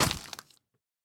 assets / minecraft / sounds / mob / zombie / step2.ogg
step2.ogg